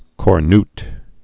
(kôr-nt, -nyt) also cor·nut·ed (-ntĭd, -ny-)